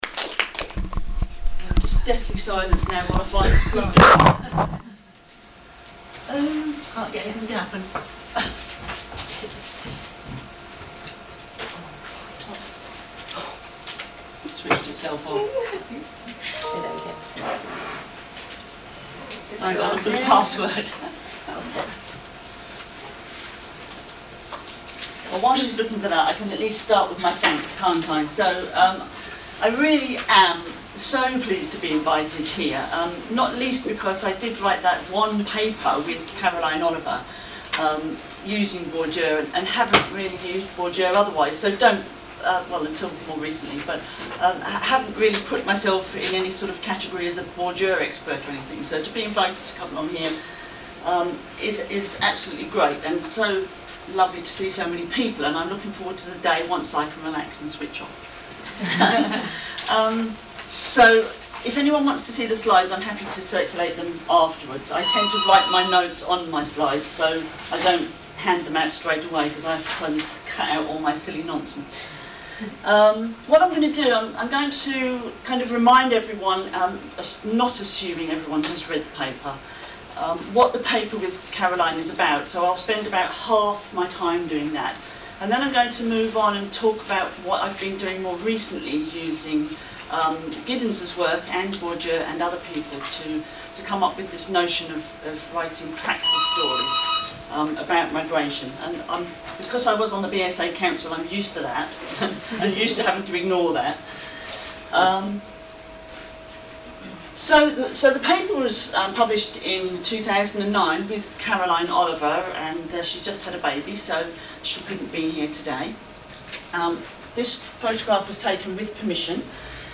keynotes